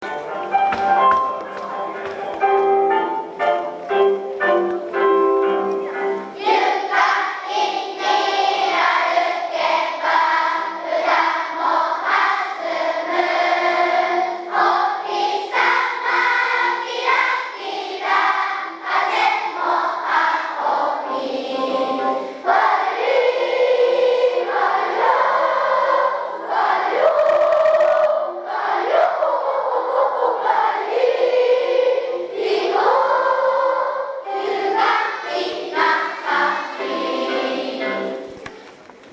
音楽集会は、４年生の発表でした。
別パートにつられないように、一所懸命歌いました。
４年生の発表①２.MP3